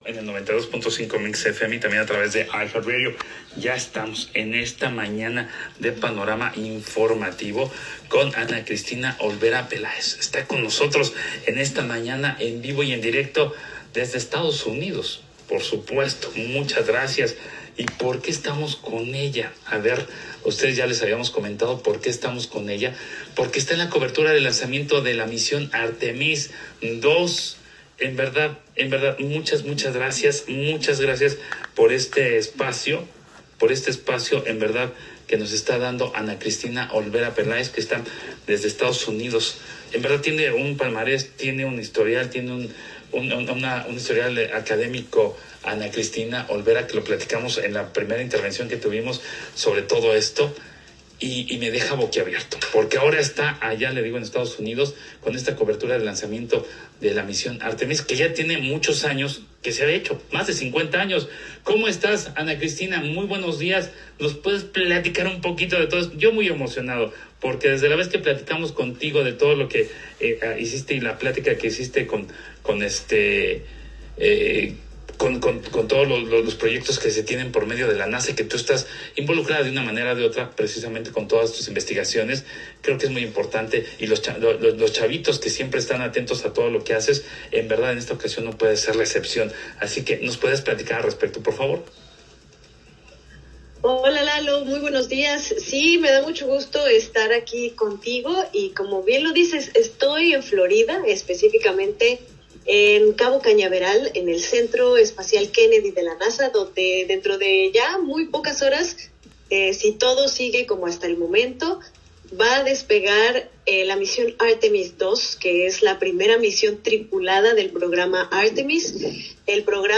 Desde Cabo Cañaveral, cobertura especial rumbo al lanzamiento de Artemis II.